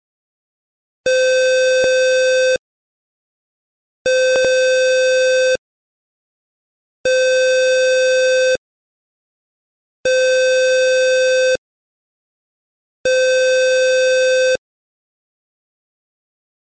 H&S – AIRPORT ALARMS & EVACUATION ALERT
STAGE 2 – ALERT TONE (1ST STAGE)
STAGE-2-1ST-STAGE-ALERT-TONE.mp3